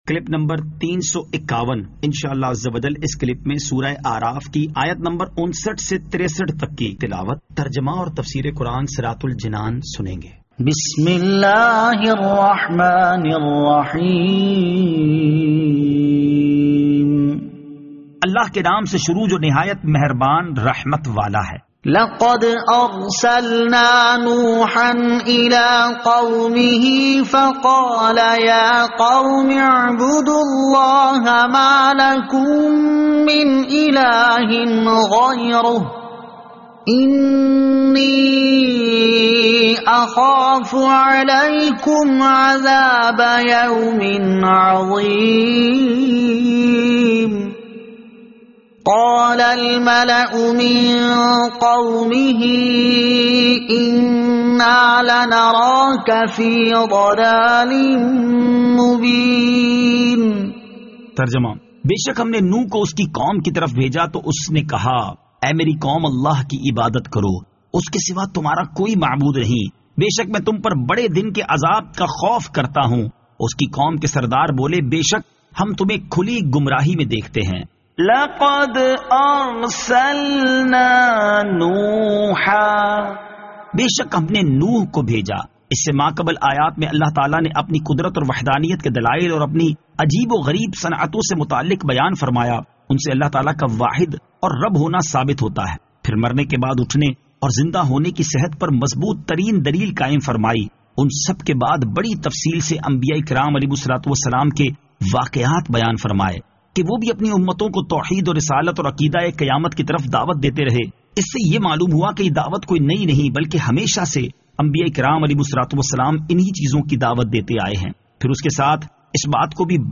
Surah Al-A'raf Ayat 59 To 63 Tilawat , Tarjama , Tafseer